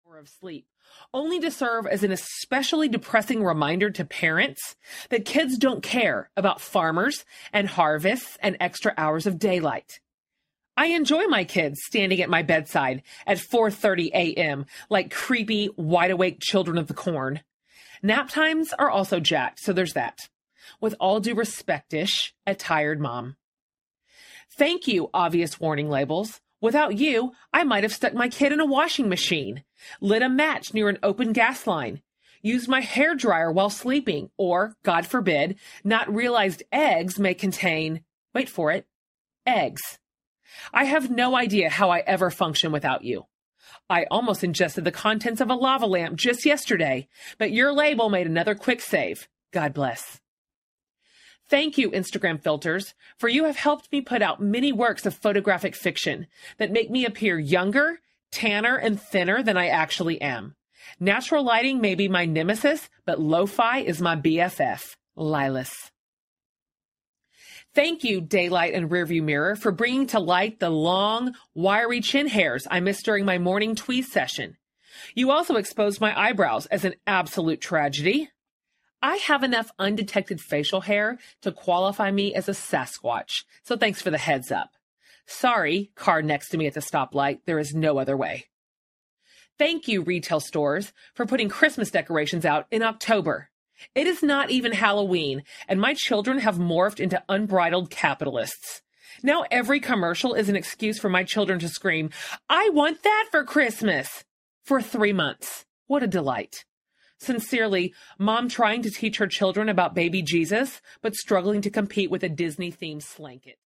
For the Love Audiobook
Narrator
Jen Hatmaker
5.72 Hrs. – Unabridged